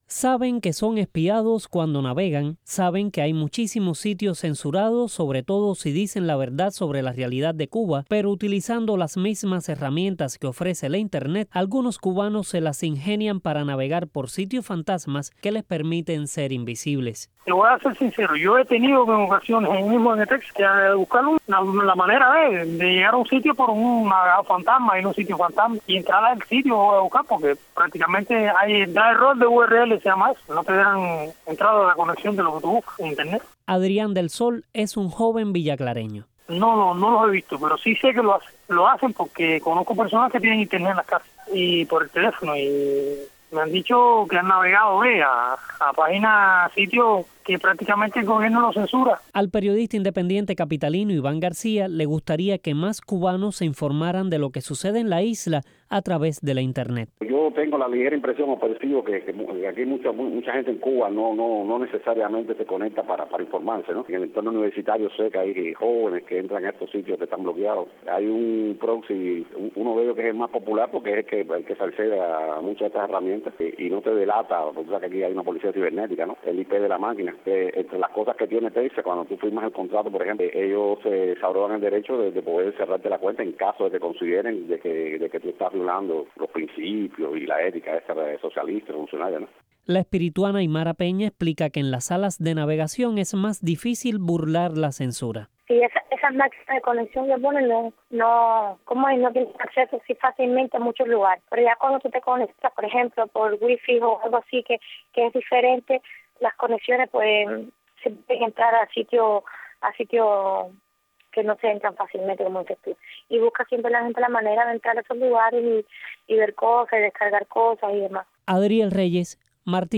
Cubanos hablan sobre el uso de proxis y páginas espejo para acceder a Internet